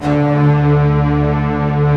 Index of /90_sSampleCDs/Optical Media International - Sonic Images Library/SI1_Fast Strings/SI1_Not Fast